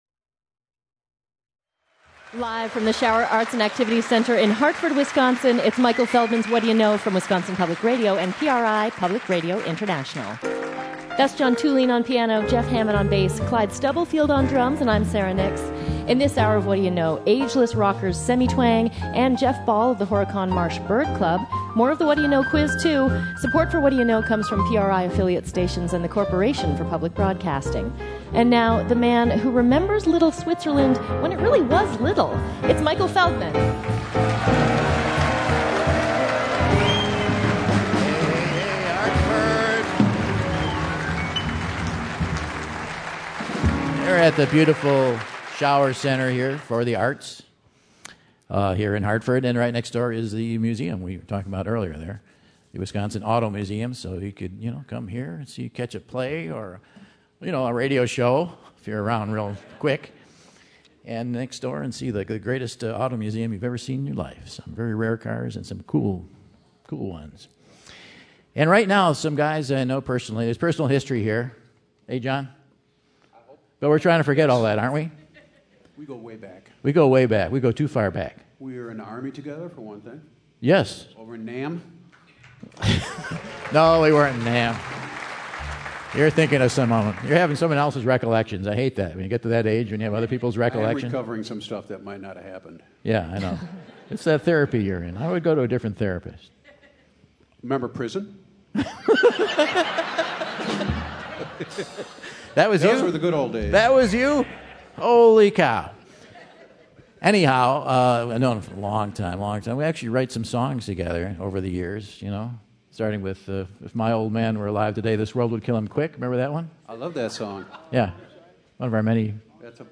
Milwaukee rockers
take the stage to play songs from their new album